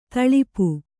♪ taḷipu